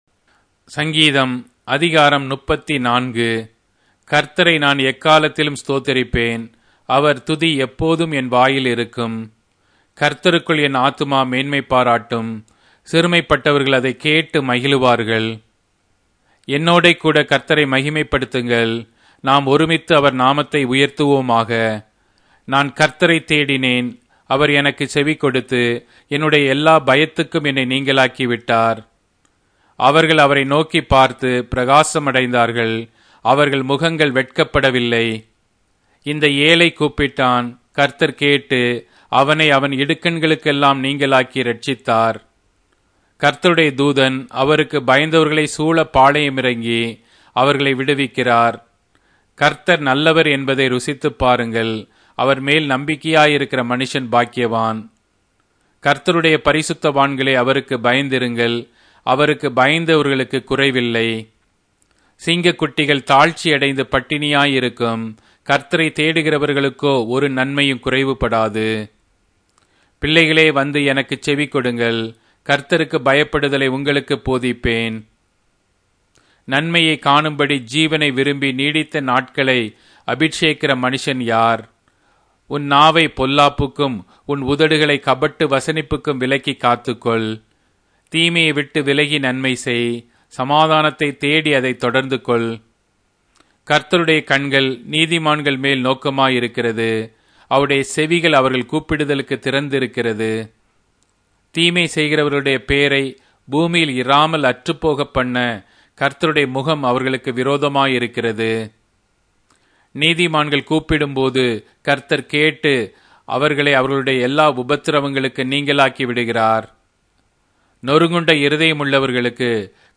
Tamil Audio Bible - Psalms 25 in Akjv bible version